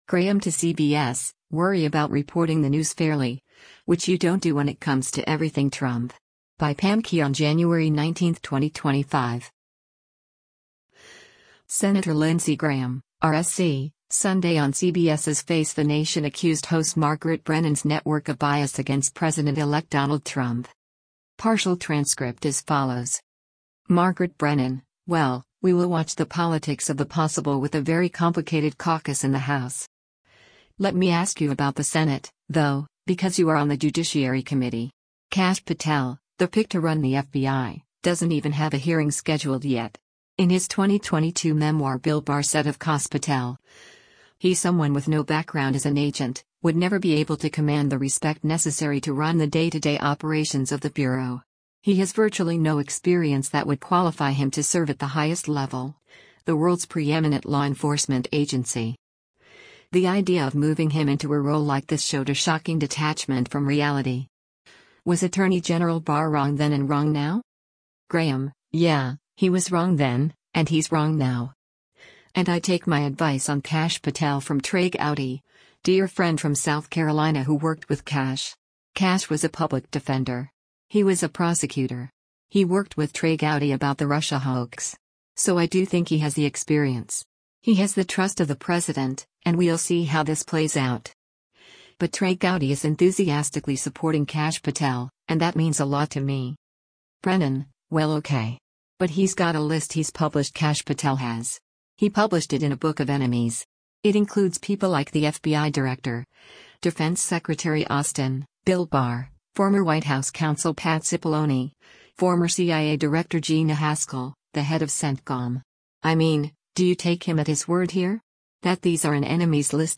Senator Lindsey Graham (R-SC) Sunday on CBS’s “Face the Nation” accused host Margaret Brennan’s network of bias against President-elect Donald Trump.